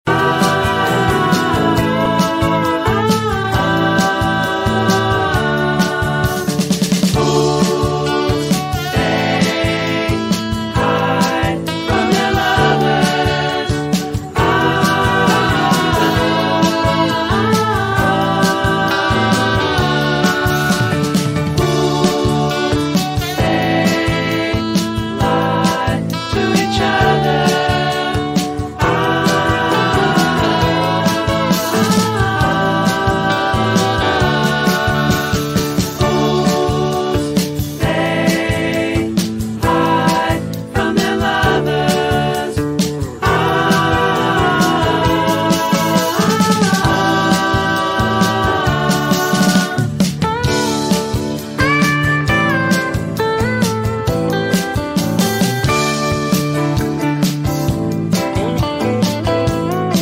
It’s retro, it’s mellow, it feels vintage yacht…